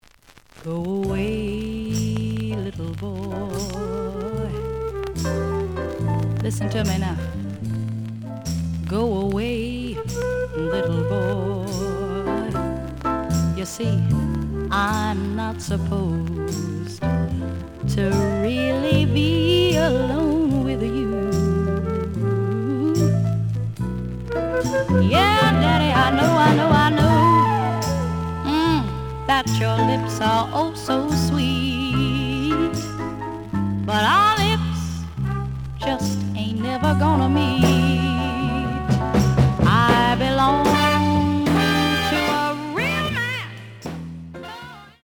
The audio sample is recorded from the actual item.
●Genre: Soul, 60's Soul
Edge warp.